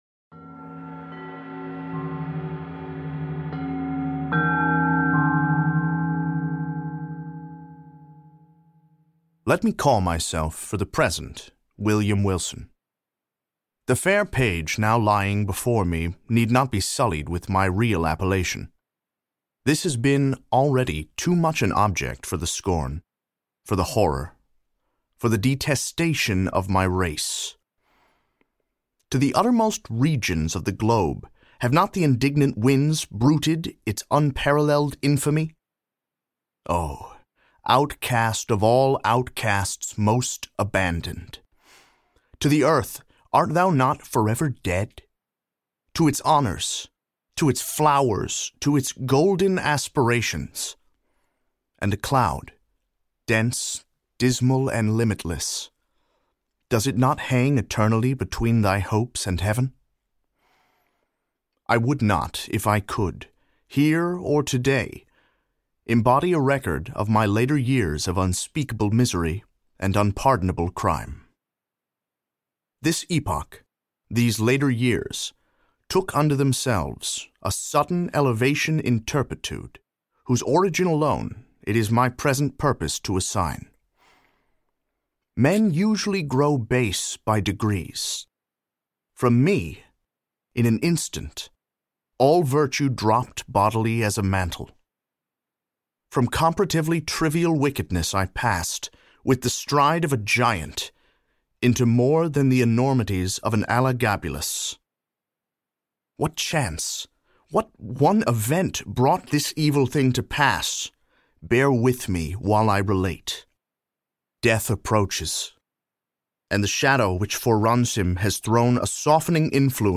Illusion audiokniha
Ukázka z knihy
The mysterious and fearsome atmosphere is emphasized by the actors’ performances, the exquisite music, and the apt graphic design on the cover.